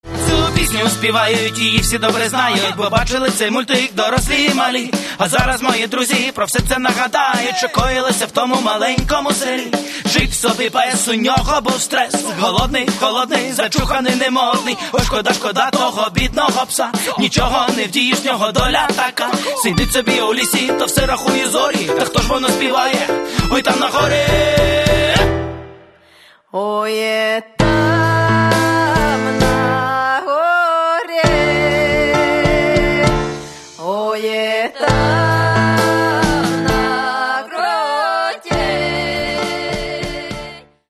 Каталог -> Поп (Легкая) -> Этно-поп
этно-поп-рок